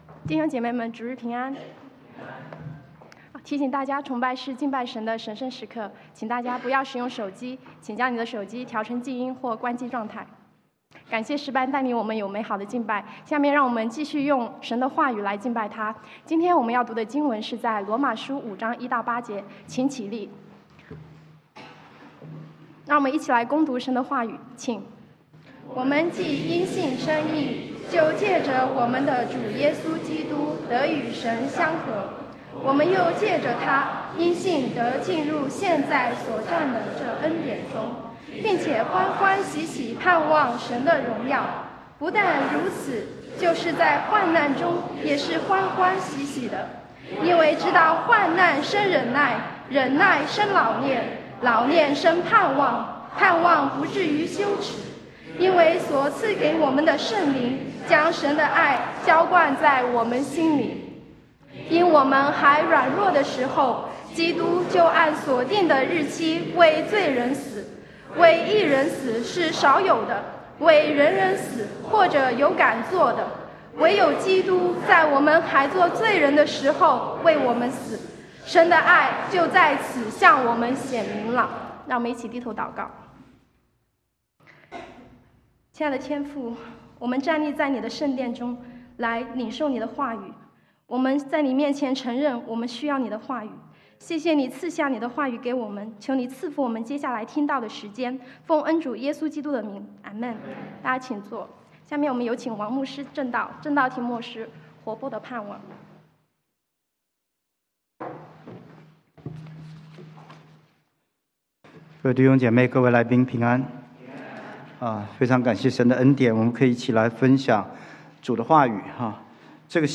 Service Type: 主日證道